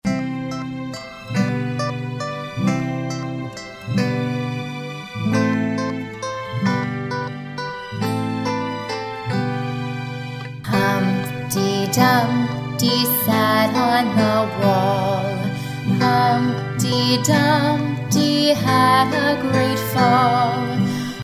Chant Lyrics and Sound Clip